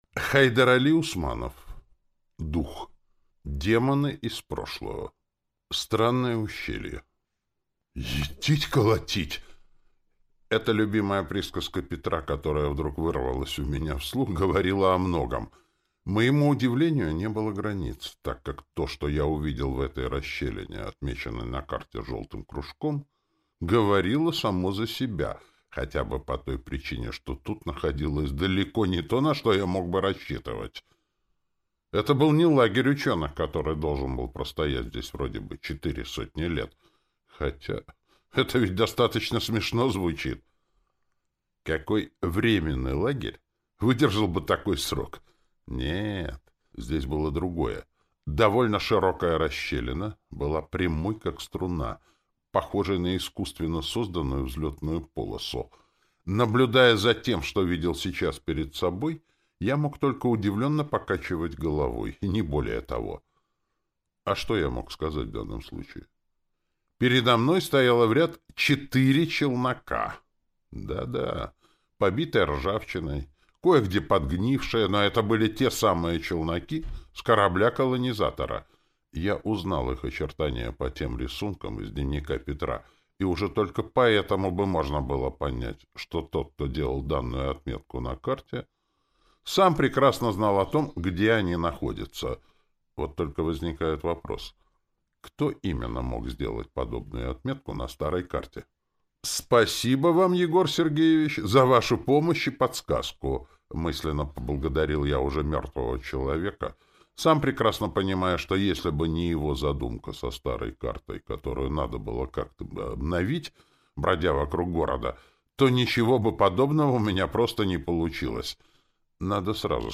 Аудиокнига Дух. Демоны из прошлого | Библиотека аудиокниг
Прослушать и бесплатно скачать фрагмент аудиокниги